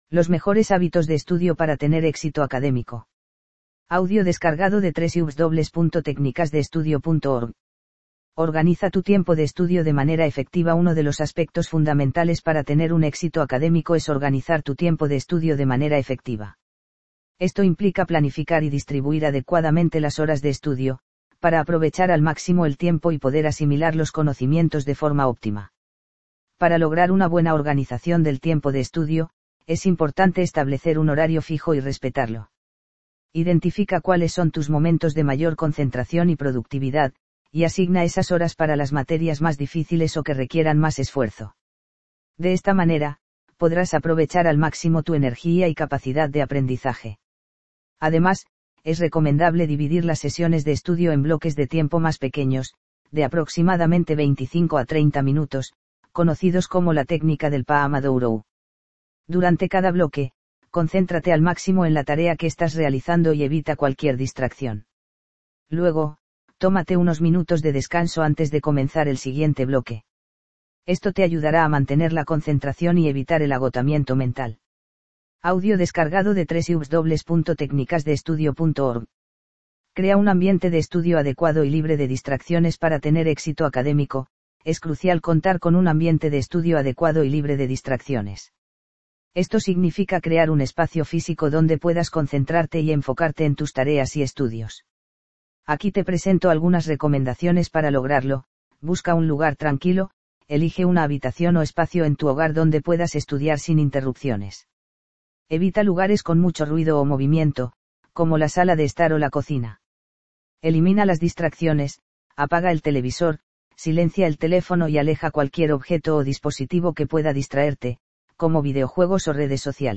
Descarga este artículo en formato de audio y sumérgete en el contenido sin complicaciones.